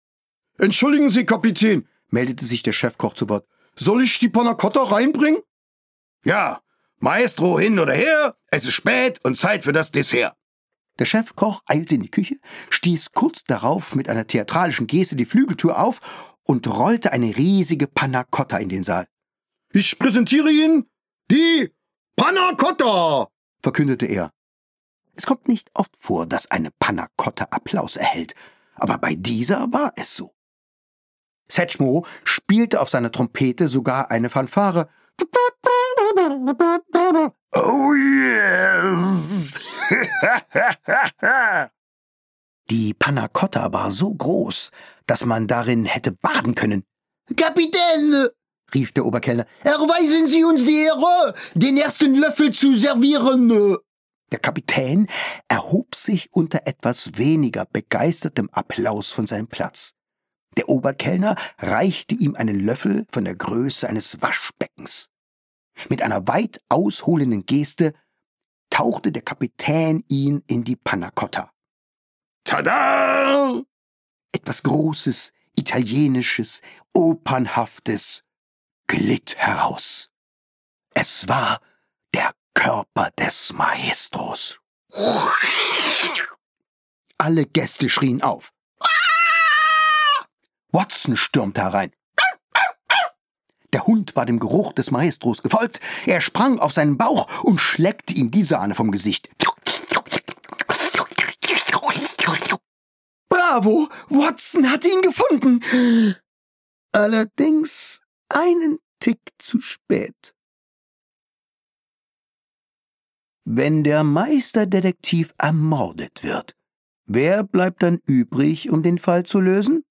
Mit viel Verve lässt er ein ganzes Ensemble lebendig werden und die Soundkulisse direkt dazu.
Gekürzt Autorisierte, d.h. von Autor:innen und / oder Verlagen freigegebene, bearbeitete Fassung.